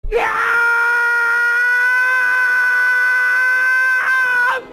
Том громко кричит яяяу